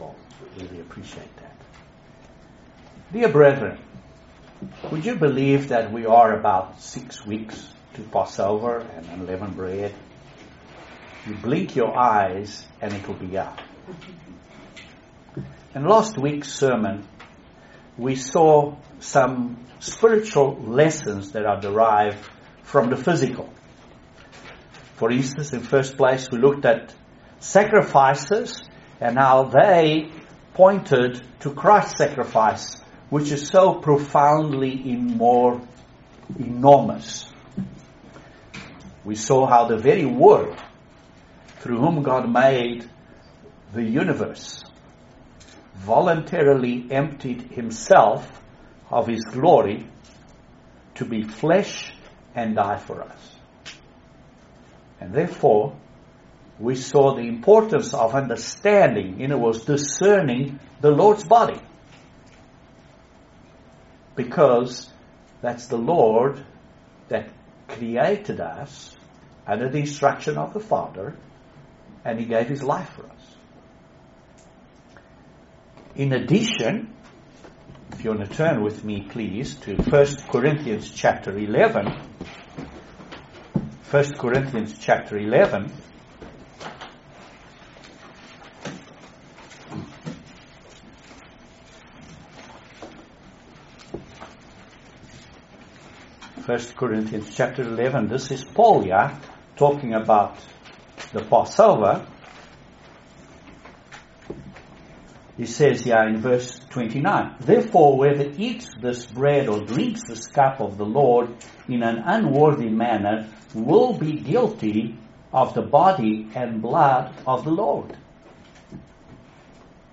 We need to prepare ourselves for God's Holy day season, and this excellent sermon is a great way to start this process.